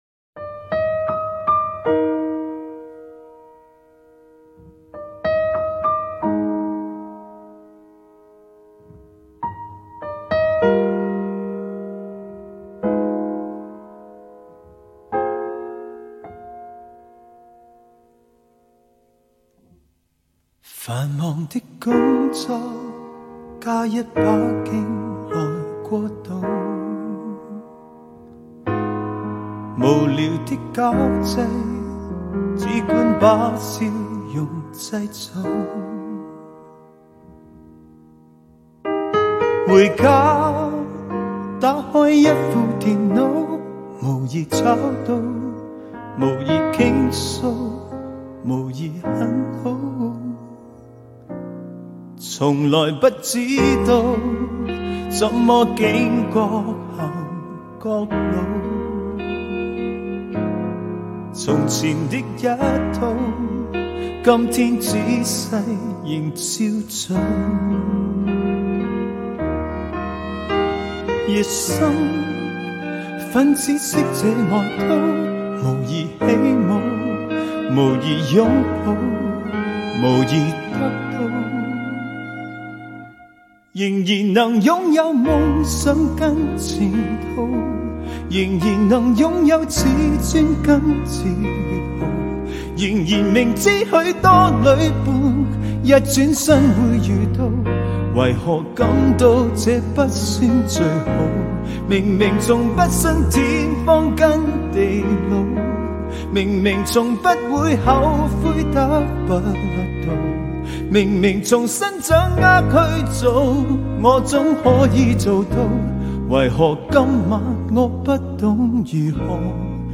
4/4 60以下